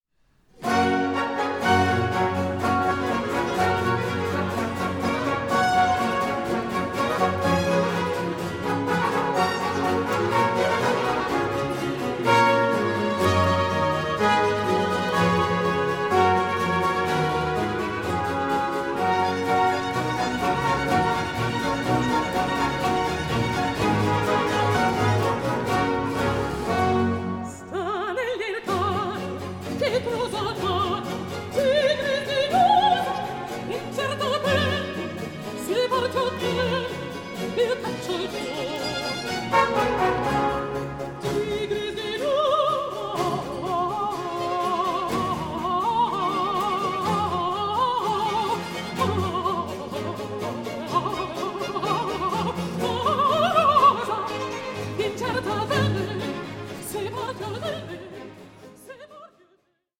INSTRUMENTALISTS PUT CENTRE STAGE
Baroque ensembles